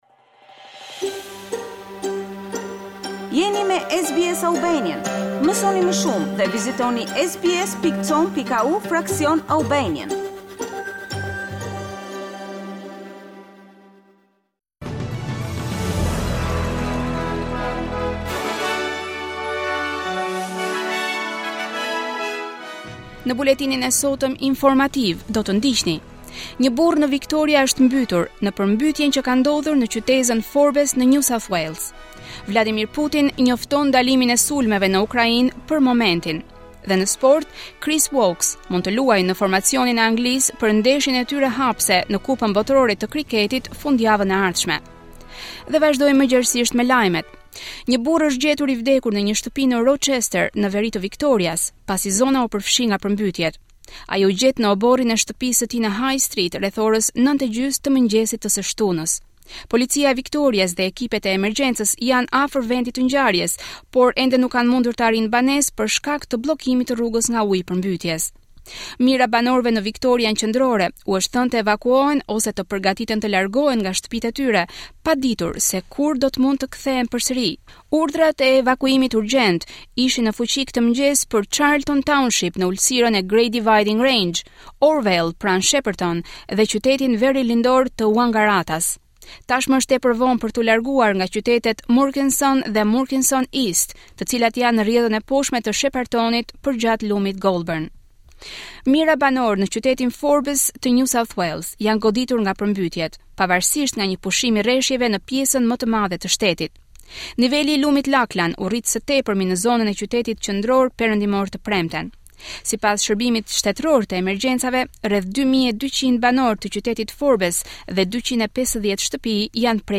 SBS News Bulletin - 15 October 2022